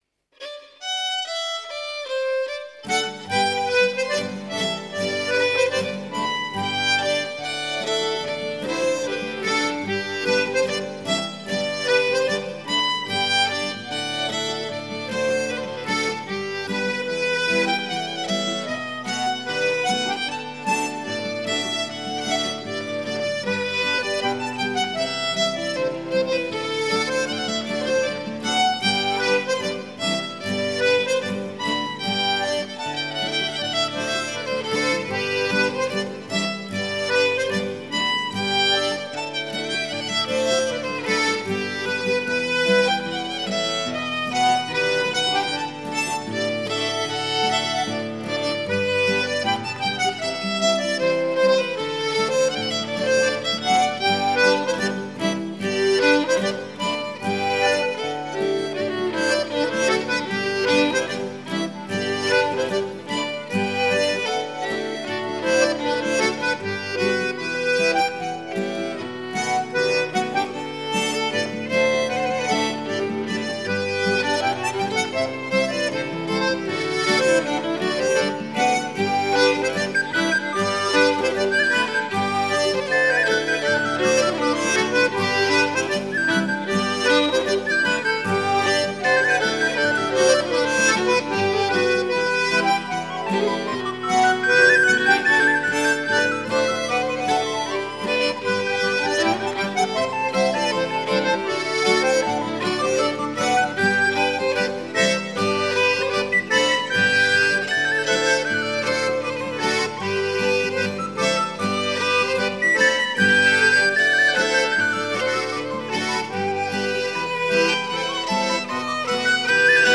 Musiques à danser
accordéon diatonique, percus
guitare, bouzouki
air tradionnel alsacien
danse : la scottisch d'Emlingen